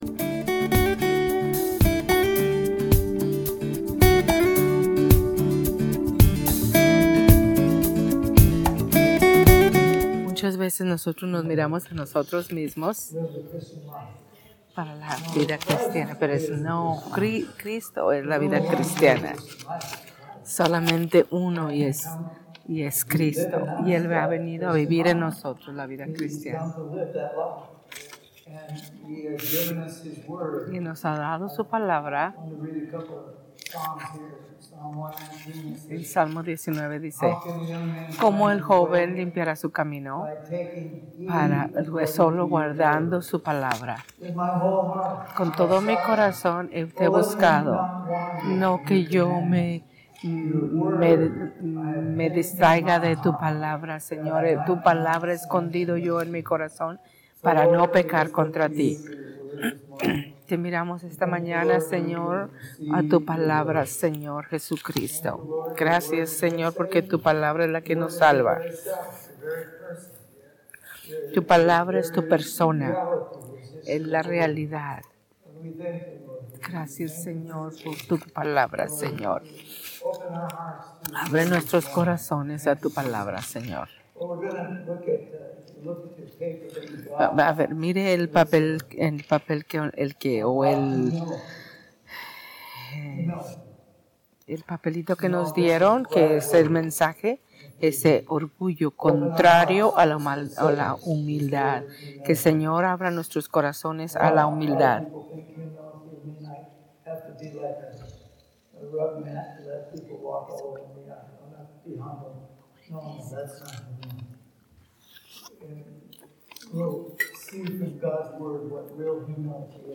Sermones en Español